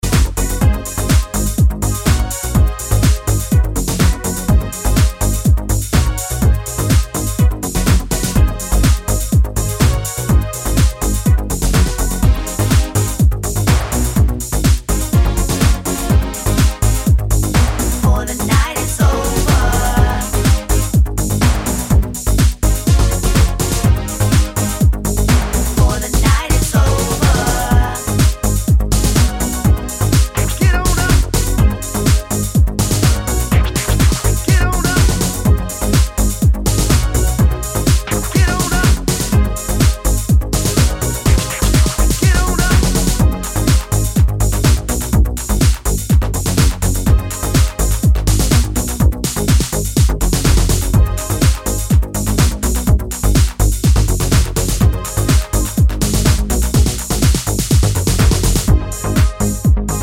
no Backing Vocals Dance 3:33 Buy £1.50